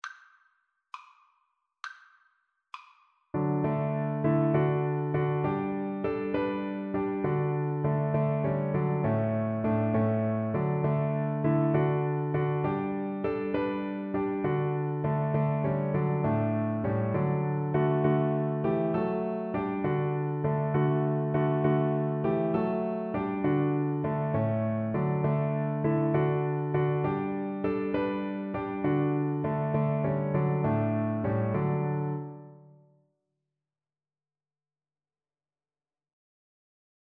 6/8 (View more 6/8 Music)